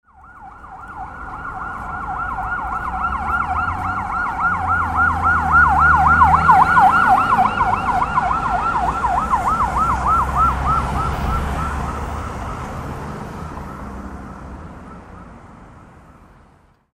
Police-car-passing-by-with-siren-very-close.mp3